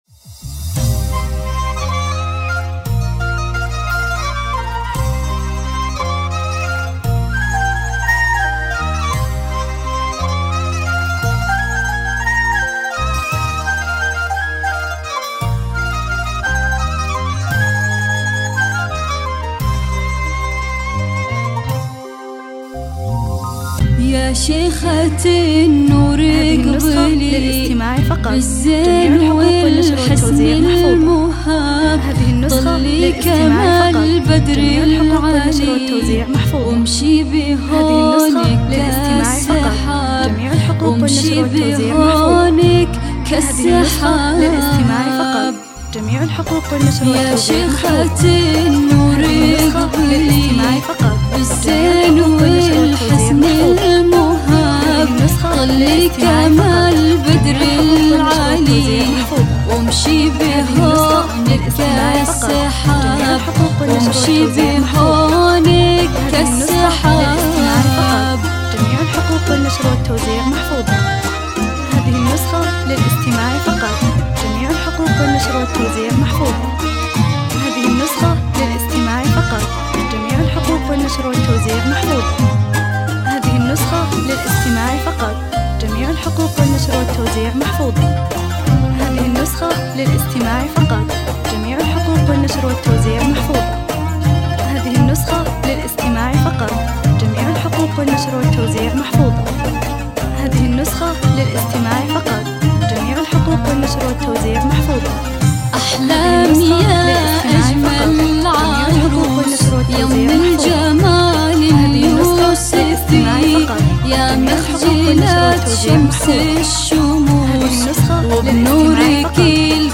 زفات اصوت نسائي